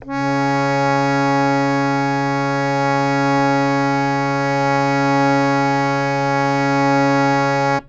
harmonium
Cs3.wav